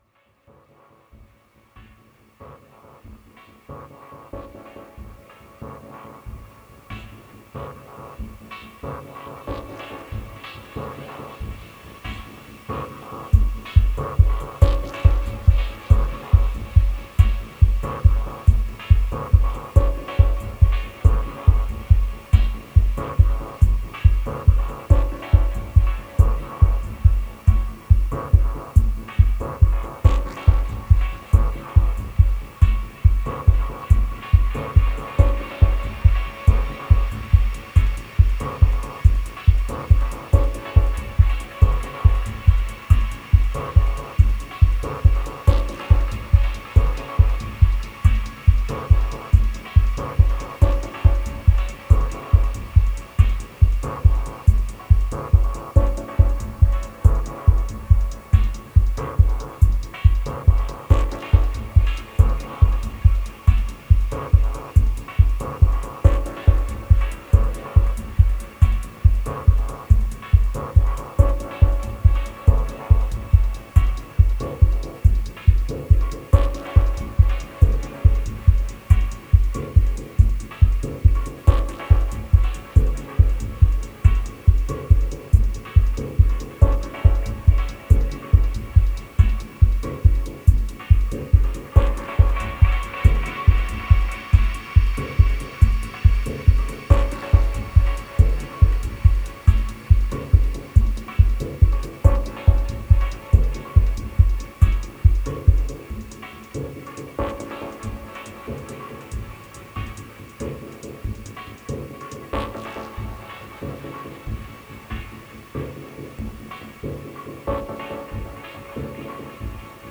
Heavy Basic Channel influence…
3 Repro-5, Valhalla Delay, Cytomic the Drop, Shimmer, Bitwig Stock plug-ins